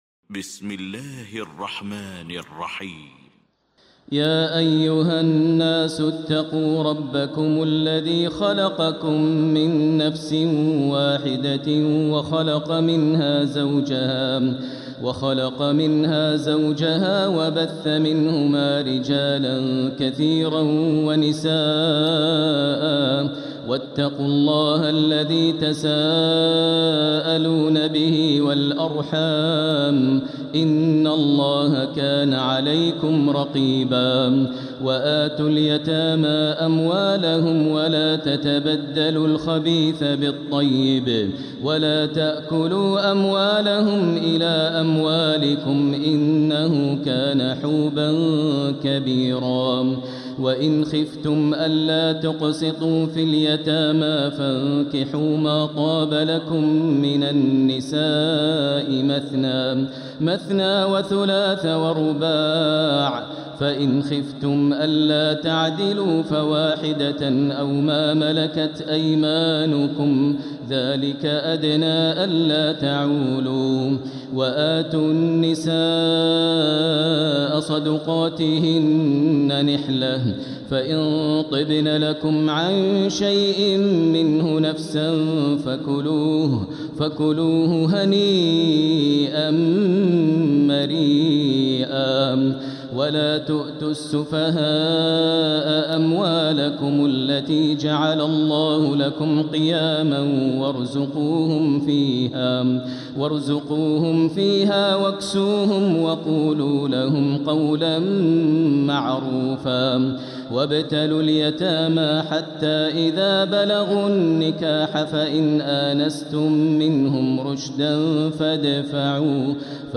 سورة النساء Surat An-Nisa > مصحف تراويح الحرم المكي عام 1447هـ > المصحف - تلاوات الحرمين